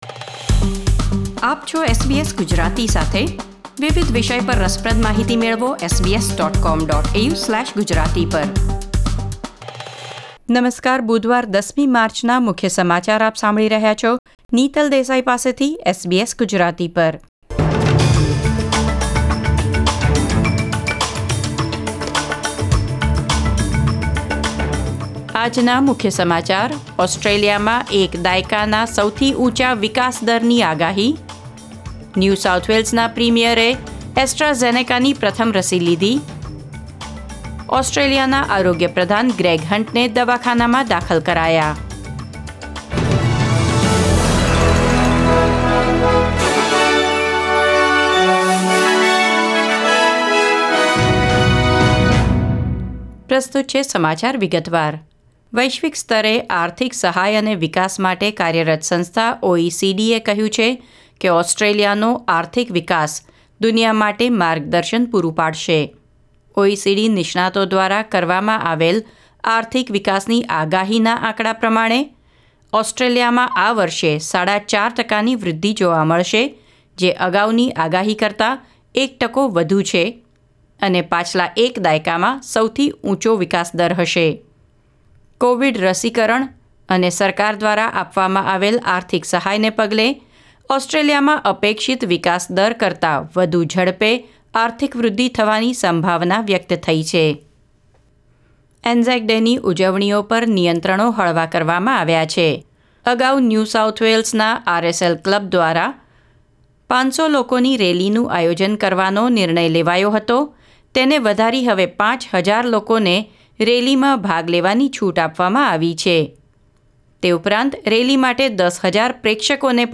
SBS Gujarati News Bulletin 10 March 2021